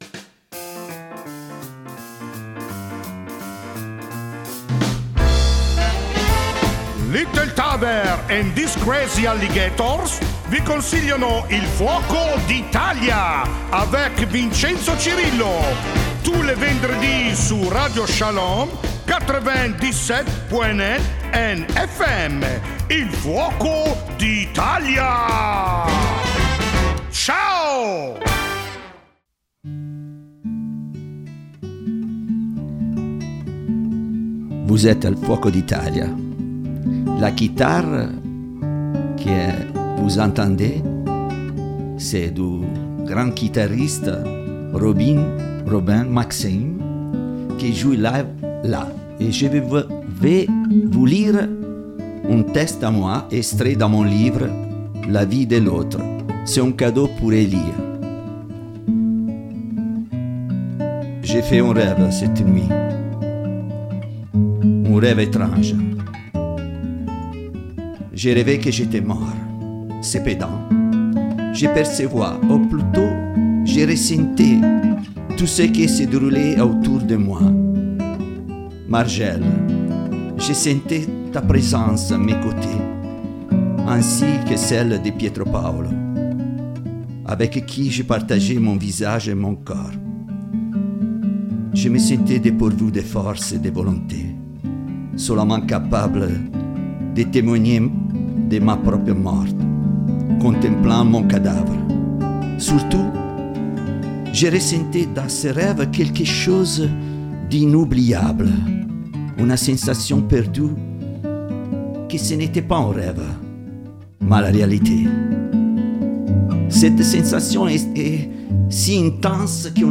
Vous pourrez également entendre plusieurs morceaux joués en direct